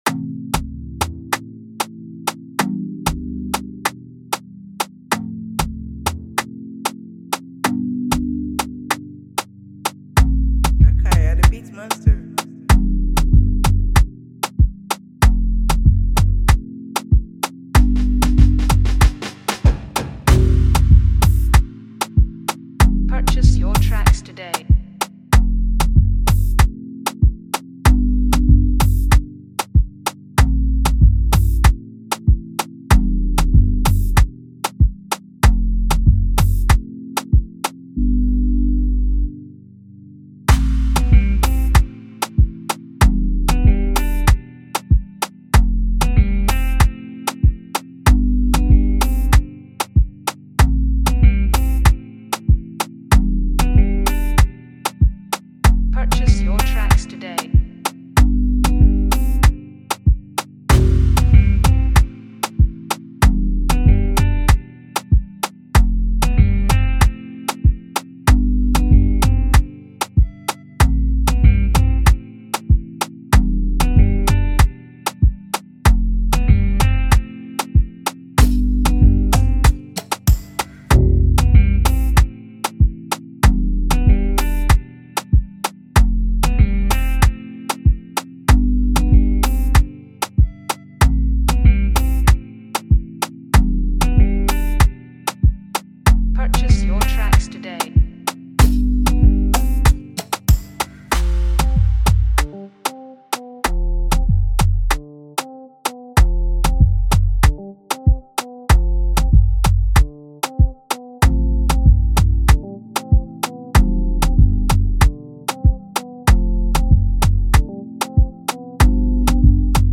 This amazing afrobeat instrumental